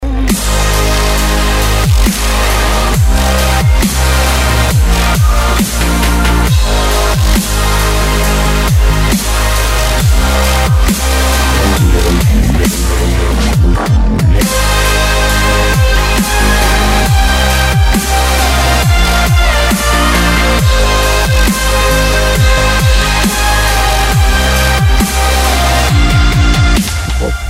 Dubstep рингтоны